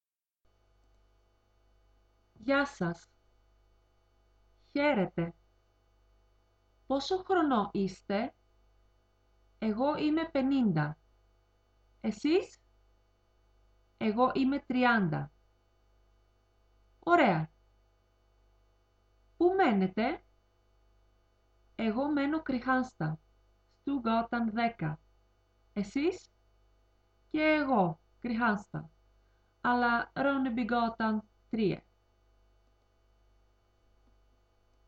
dialog3.mp3